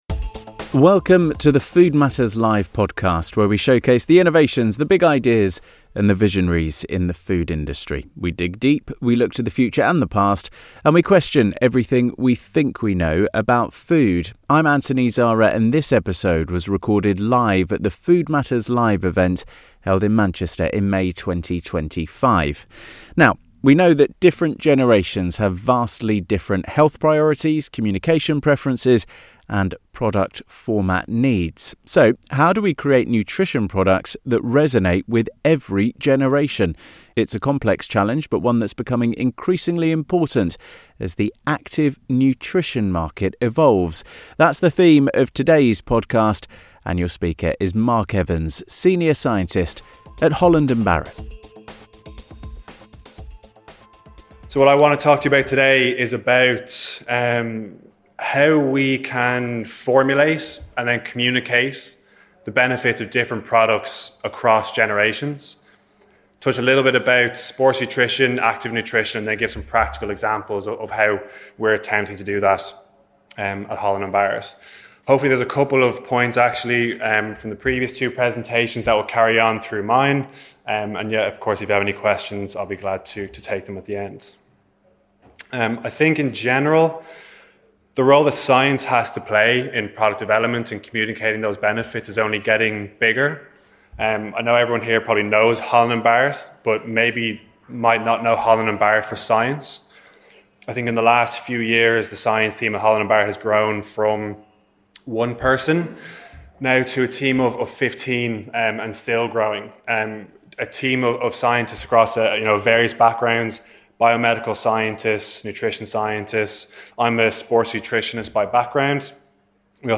In this episode of the Food Matters Live podcast, recorded at our event in Manchester in May 2025, we explore how brands can successfully navigate these generational differences to create products that work across multiple demographics, from understanding what drives each age group to practical formulation strategies.